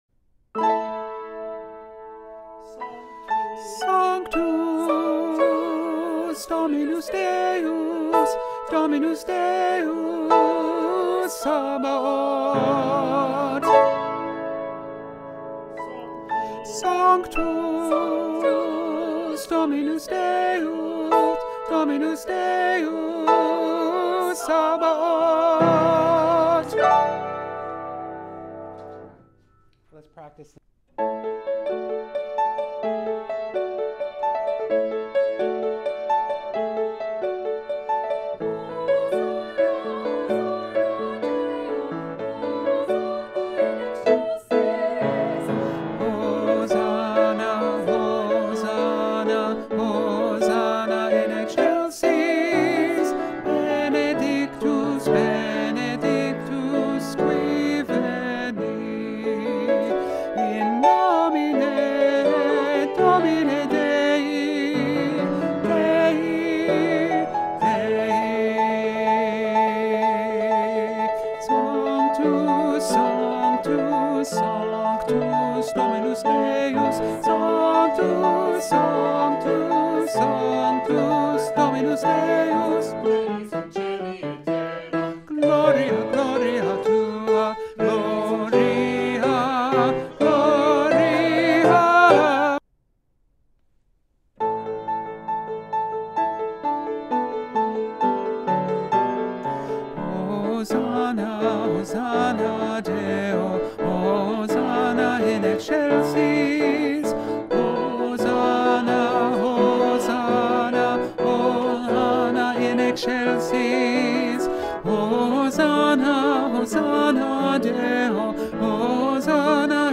Lo ULTIMO Tenores
Sanctus-Festiva-Tenor.mp3